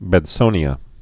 (bĕd-sōnē-ə)